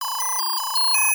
reminder.wav